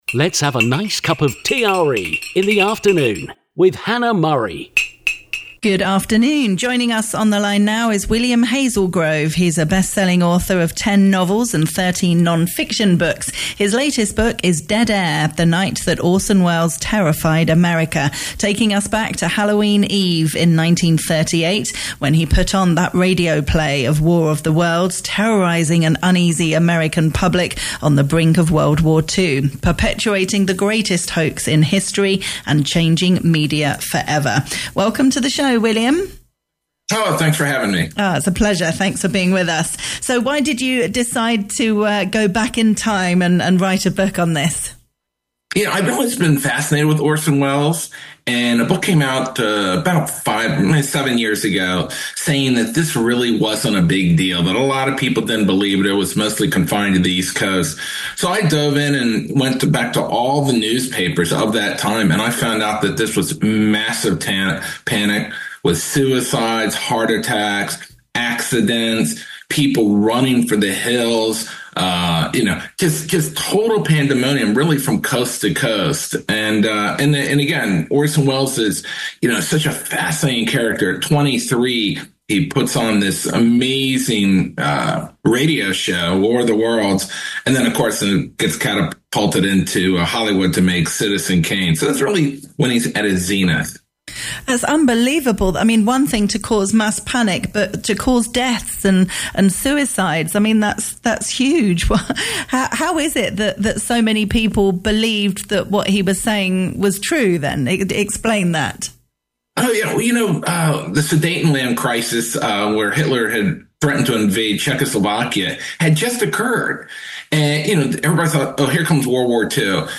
TALK RADIO EUROPE INTERVIEW ON DEAD AIR